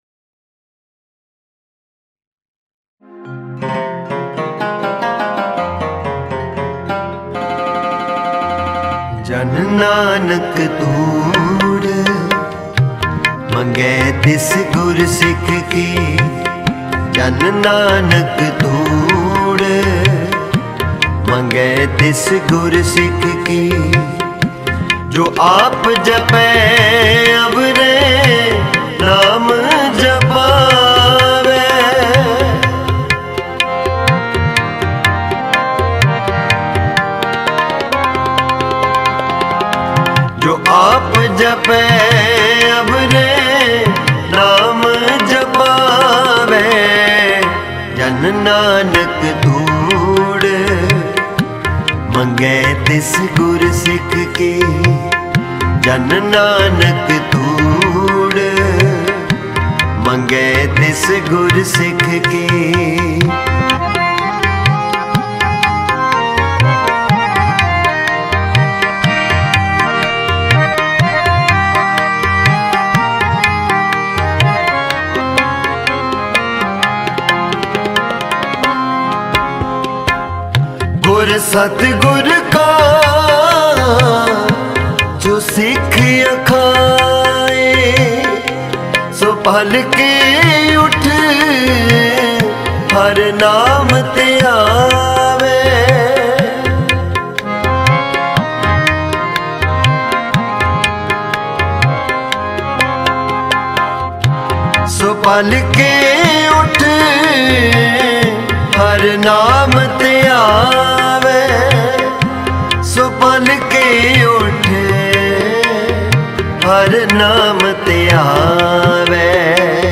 Gurbani Kirtan